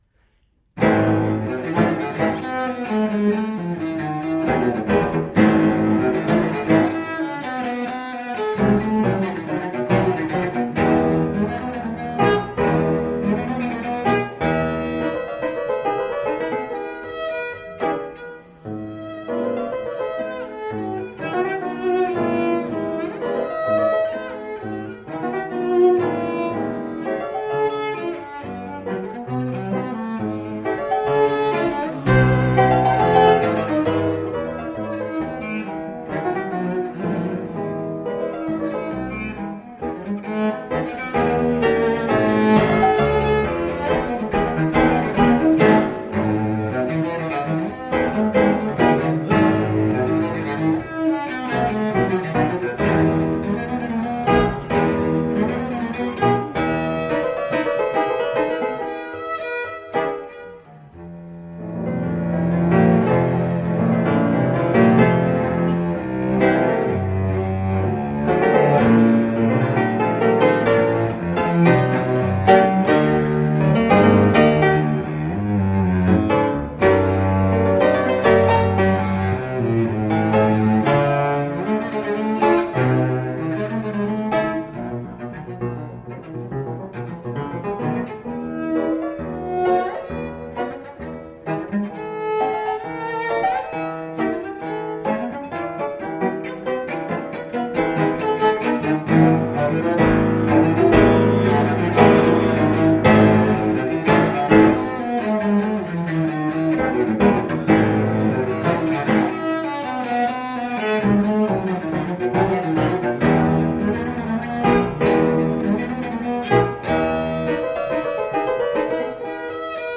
Baroque oboist extraordinaire.